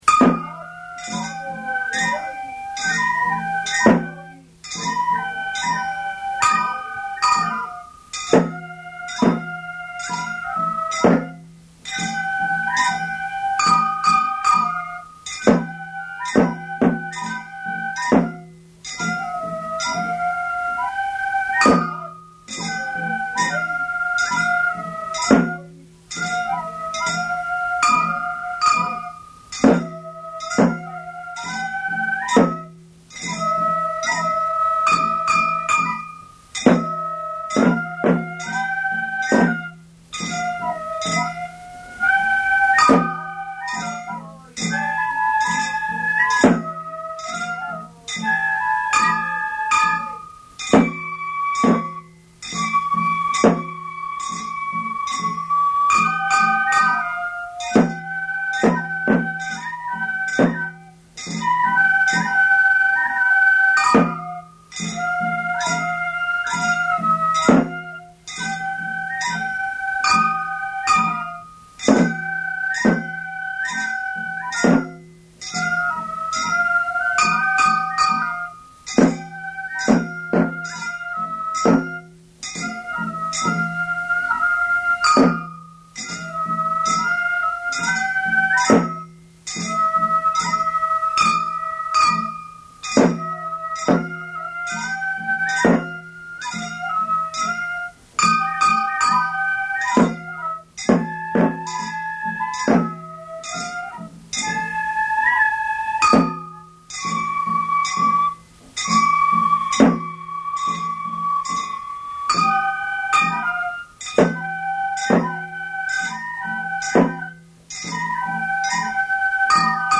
どの囃子もパートの繰り返しだがこの曲だけ鉦、太鼓、笛の繰り返す位置が違っておりこの曲名がついている。
昭和62年11月1日　京都太秦　井進録音スタジオ